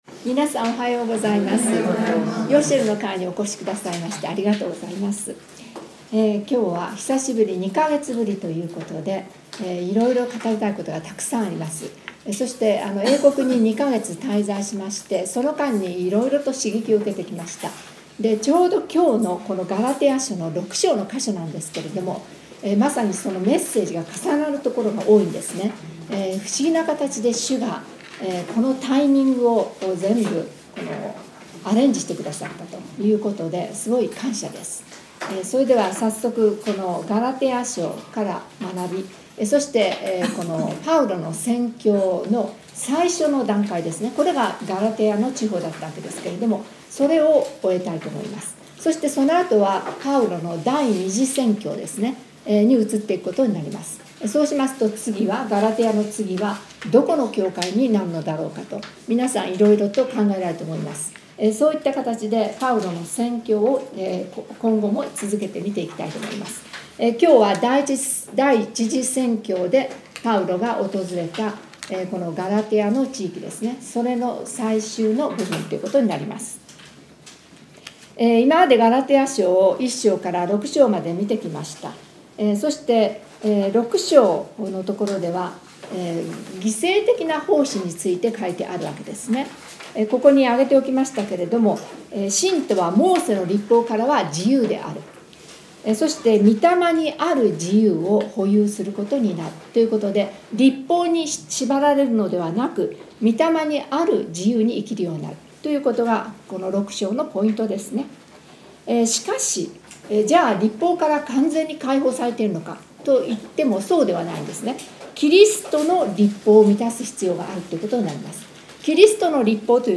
5月8日に第38回ヨシェルの会が開催されました。